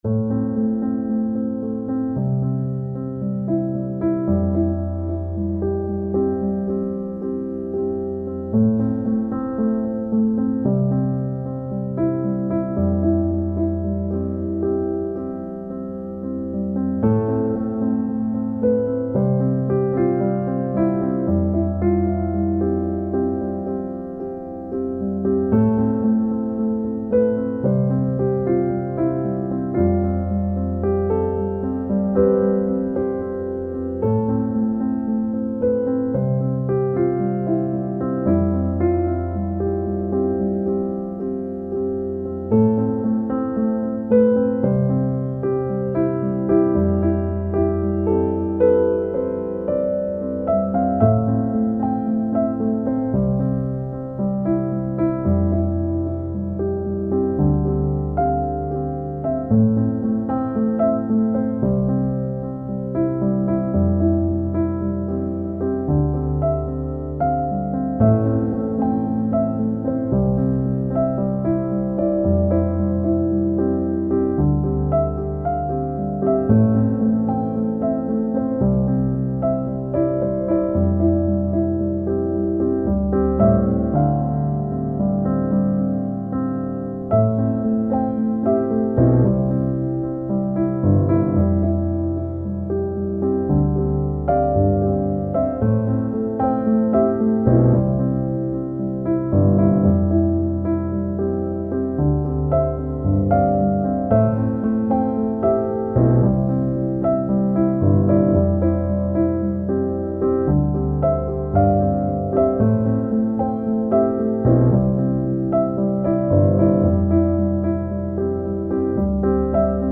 موسیقی کنار تو
موسیقی بی کلام Easy listening آرامش بخش الهام‌بخش پیانو